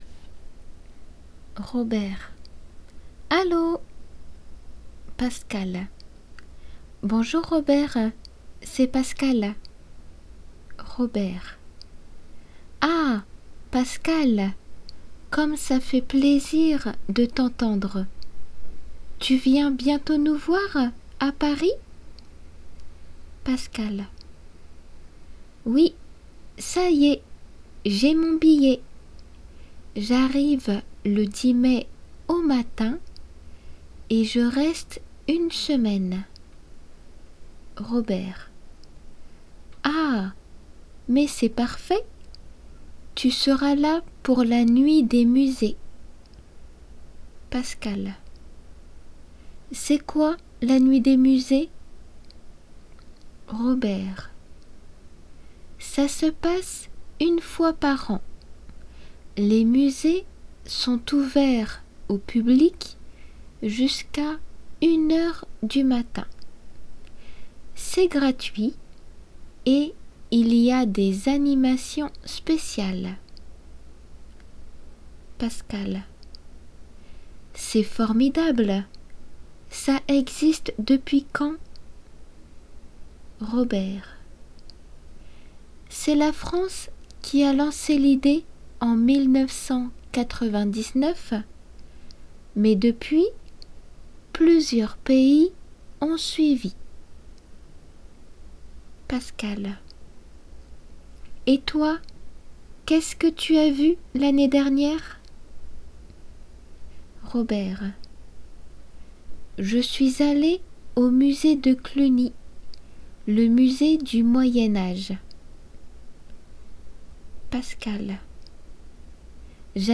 二人の会話を聞きましょう。　Parisに旅行で訪れようとしている友人との会話です。